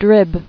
[drib]